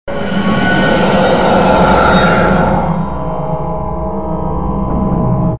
cloak_osiris.wav